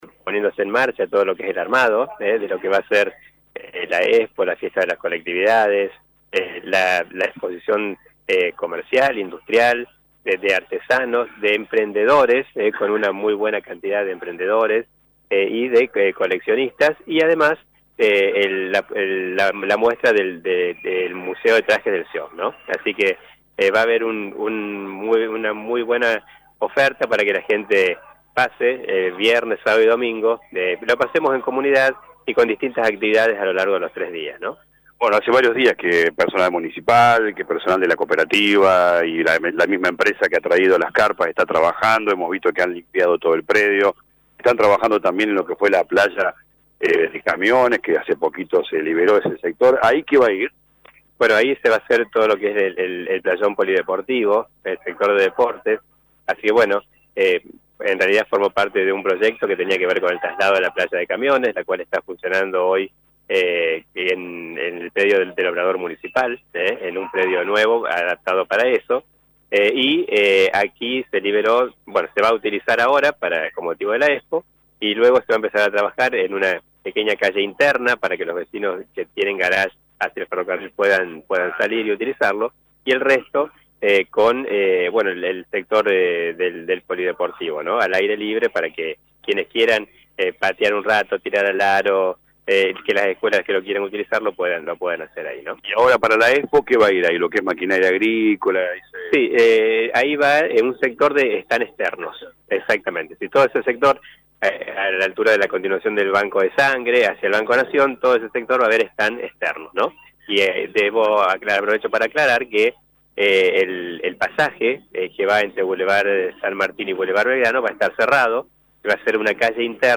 Desde el predio, el intendente Municipal Dr. Gustavo Tevez en diálogo con LA RADIO 102.9 FM repasó las actividades que comenzarán el viernes 16 (decretado feriado local) con el acto central y desfile de instituciones en la Plaza San Martín.